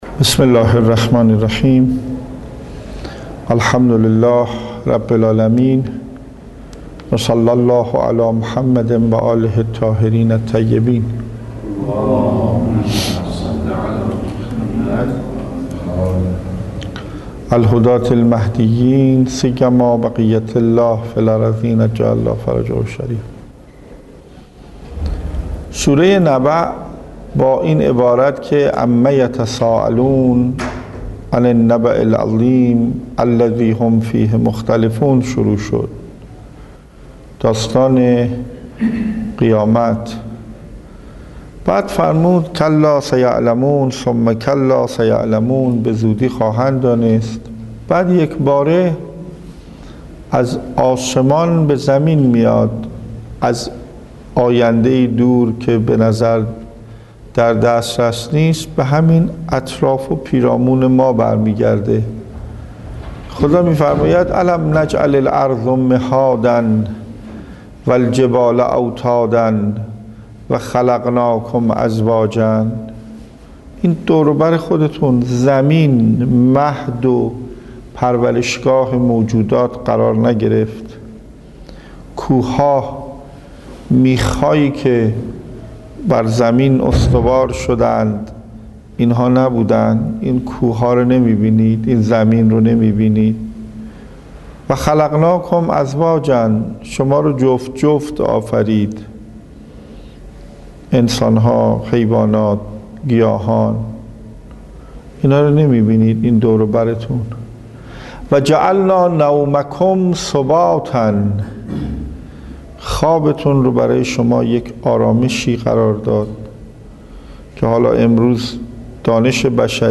تفسیر ، سوره نبا